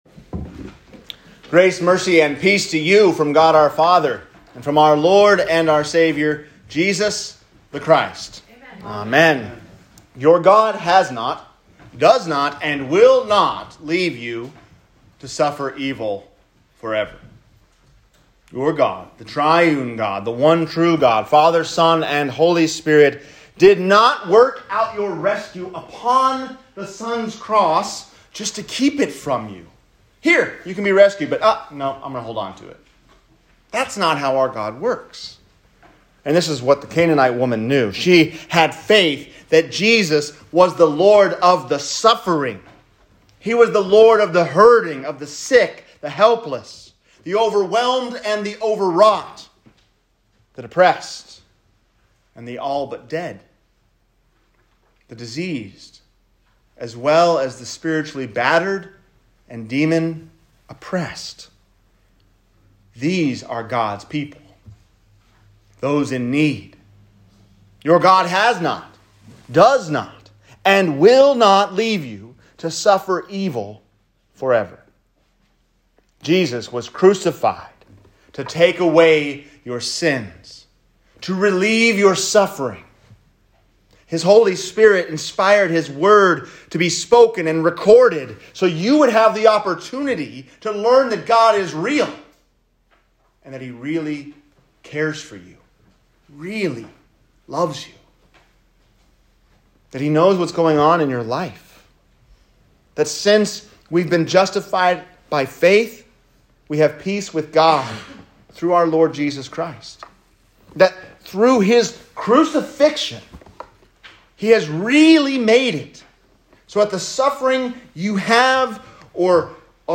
3-13-22-sermon_reminiscere.m4a